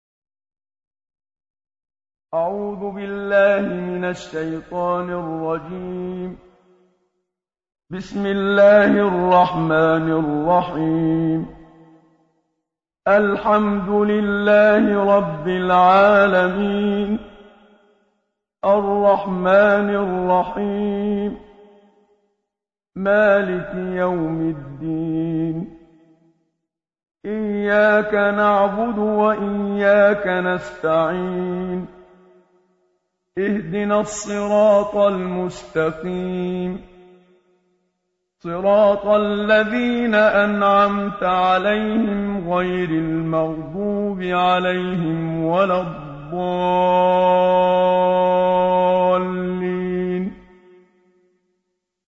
حمد منشاوی ترتیل